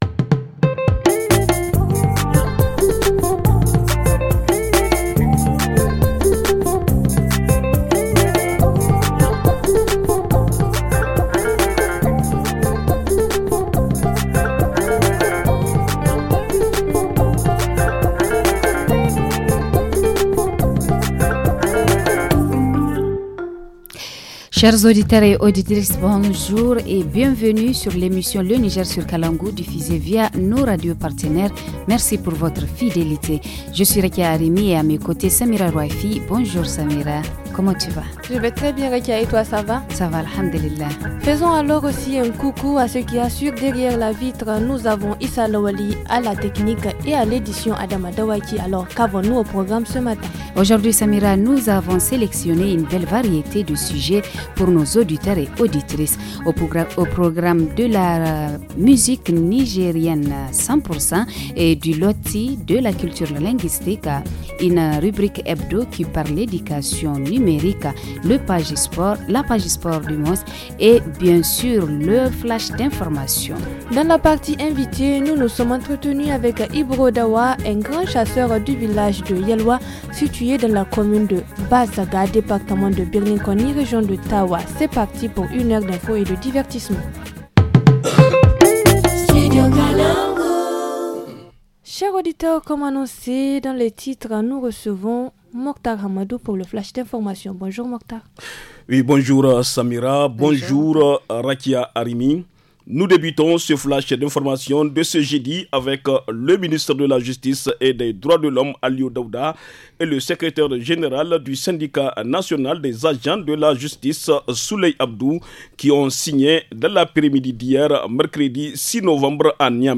Dans la section « Éducation numérique », nous vérifions le prix d’un poisson estimé à 2,6 millions de dollars. En reportage région, zoom sur la rentrée scolaire à Bankilaré, zone d’insécurité.